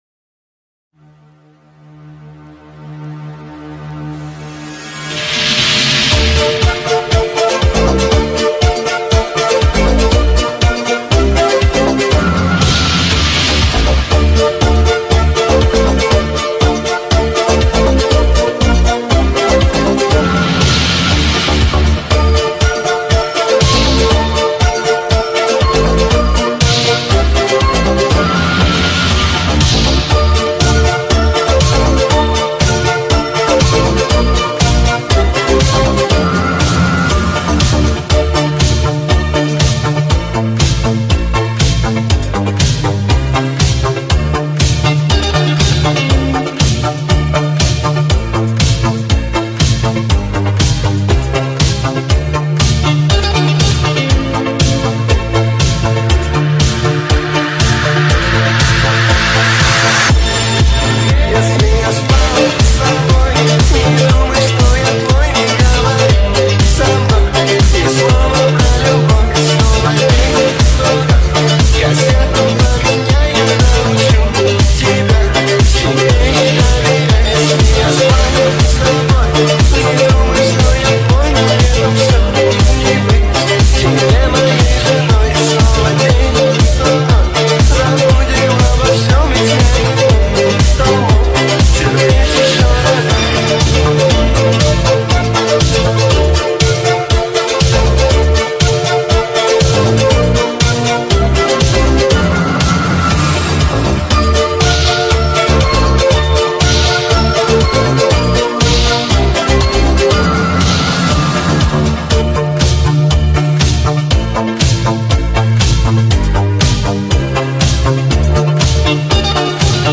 это яркий и энергичный трек в жанре поп и хип-хоп